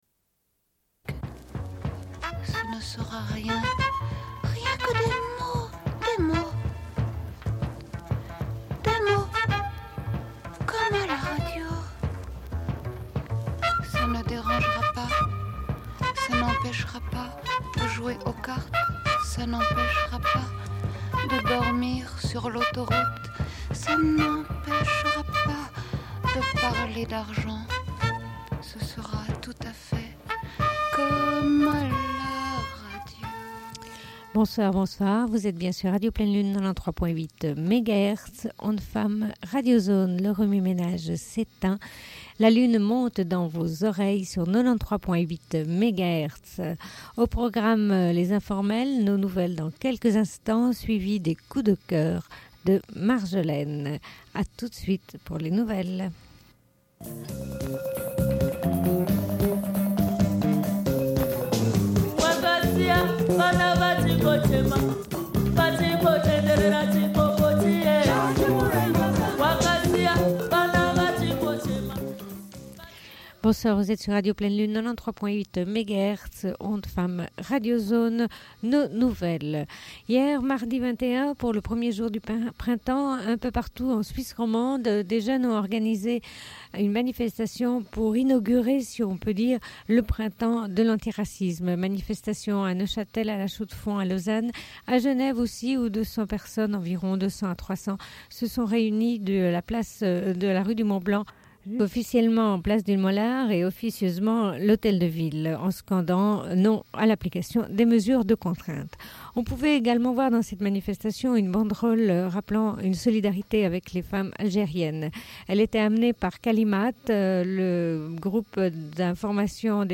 Une cassette audio, face B28:58